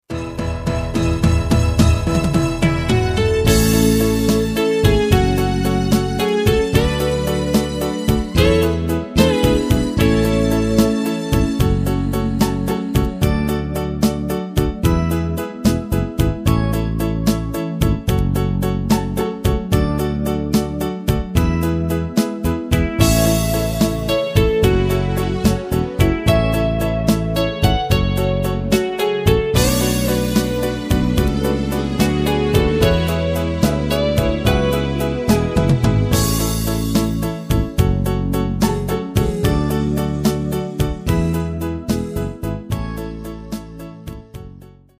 Dema w formacie mp3.